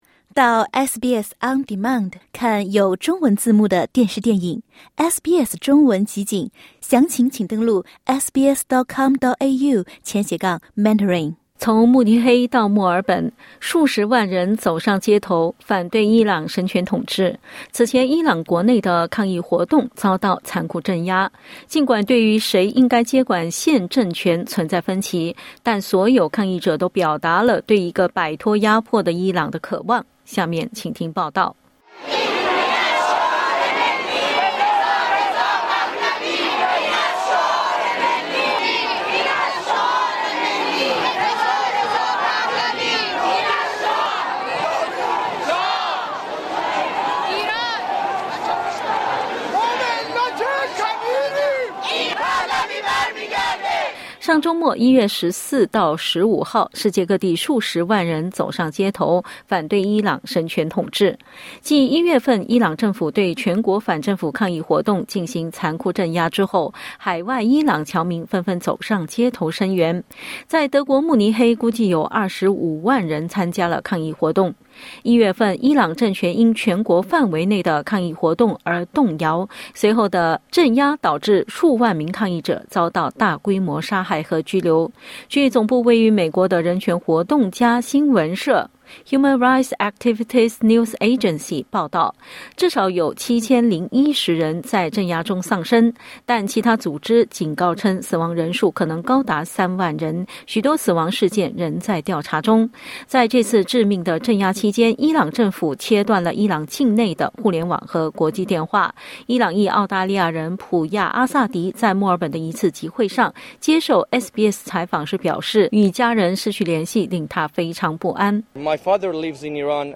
从慕尼黑到墨尔本，数十万人走上街头，反对伊朗神权统治，此前伊朗国内的抗议活动遭到残酷镇压。 点击音频收听详细采访